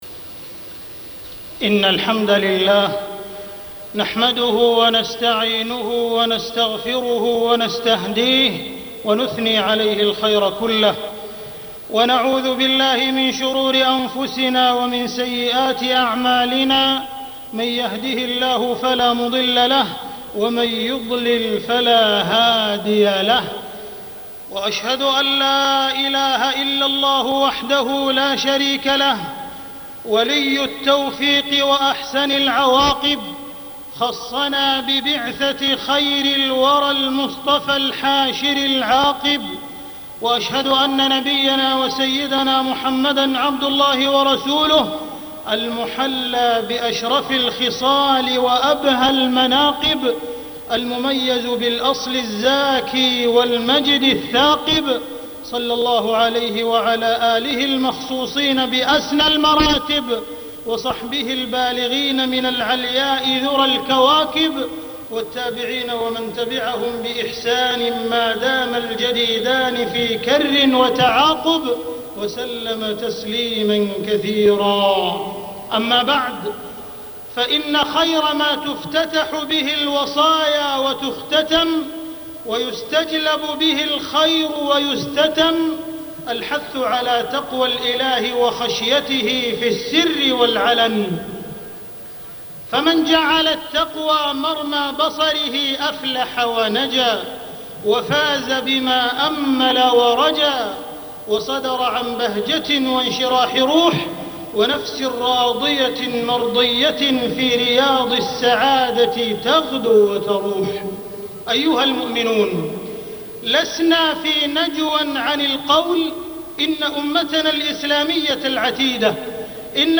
تاريخ النشر ٦ ربيع الأول ١٤٢٦ هـ المكان: المسجد الحرام الشيخ: معالي الشيخ أ.د. عبدالرحمن بن عبدالعزيز السديس معالي الشيخ أ.د. عبدالرحمن بن عبدالعزيز السديس حديث عن المصطفى المختار